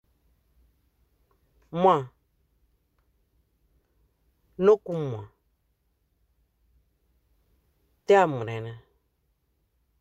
Accueil > Prononciation > mw > mw